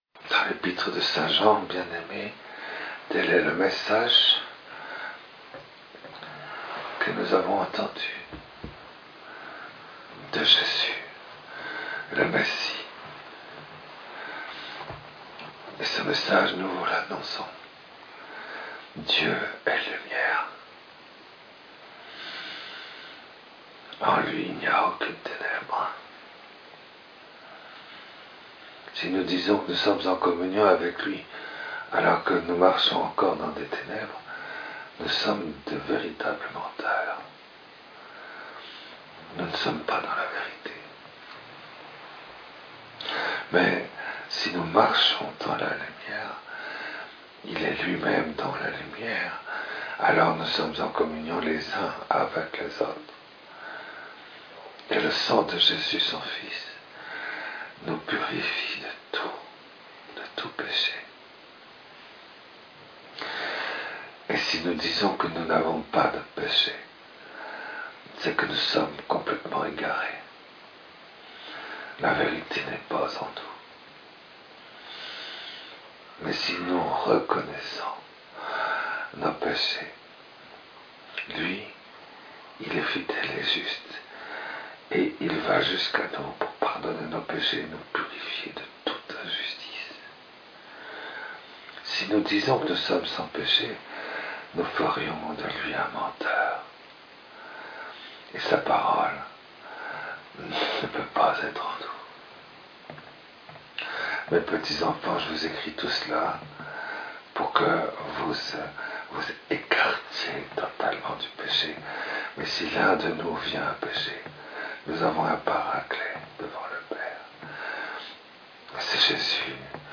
Lectures et Homélie du 28 décembre, Fête des Saints Innocents